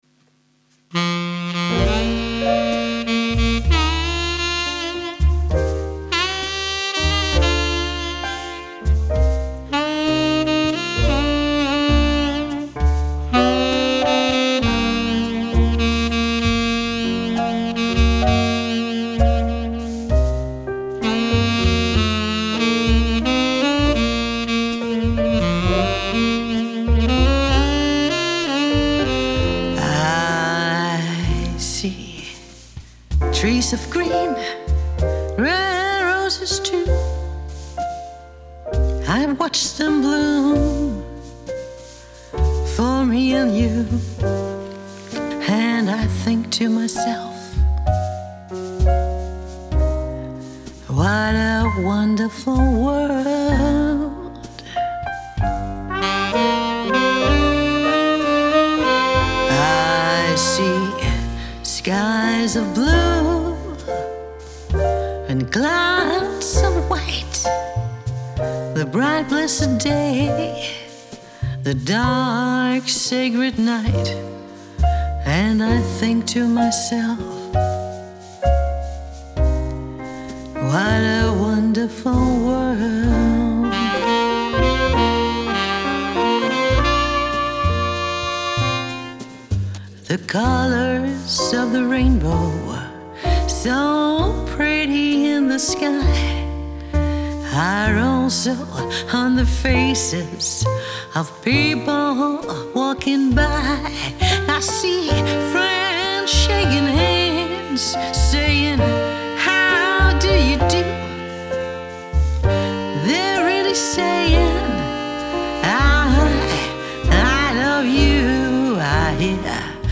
VIP Sangerinde Lounge Diva stemning Solo - Duo - Trio